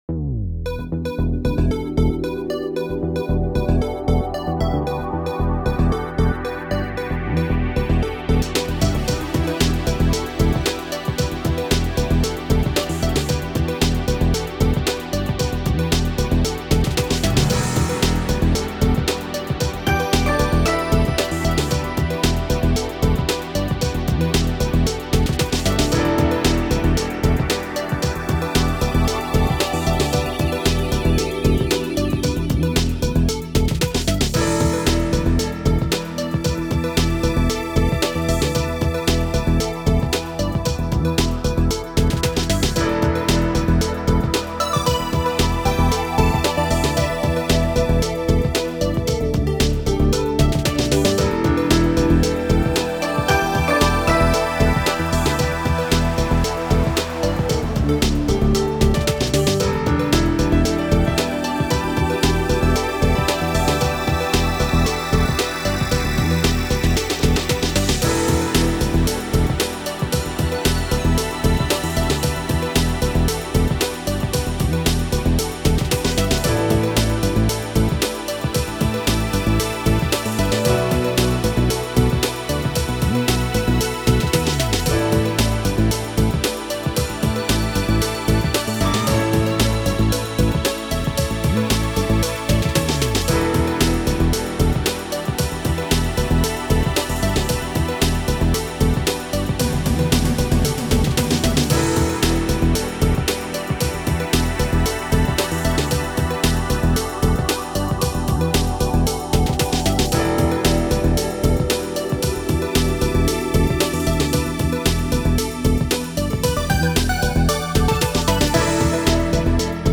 Synthpop
Style: Synthpop
Digging the drums here. Very nice and relaxing.